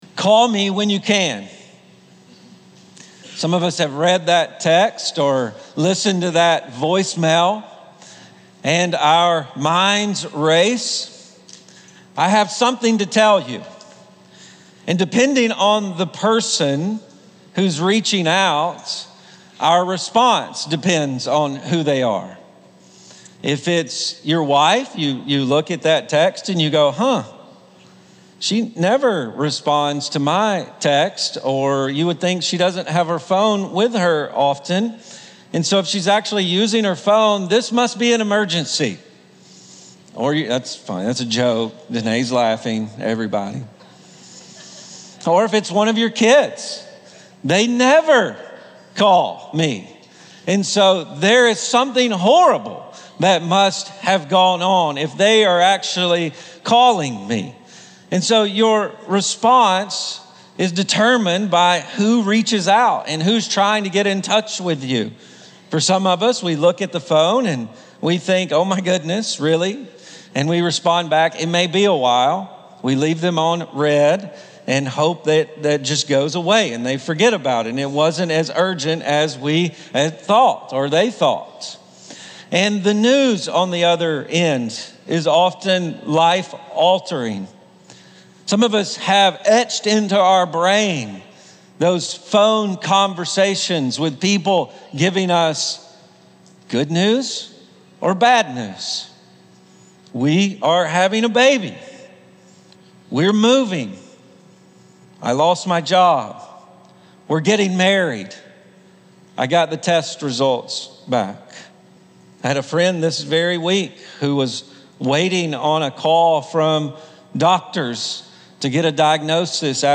Ashland Church Sermons 1 & 2 Thessalonians (1 Thessalonians 3:13-16) May 19 2025 | 00:42:13 1x 00:00 / 00:42:13 Subscribe Share Spotify RSS Feed Share Link Embed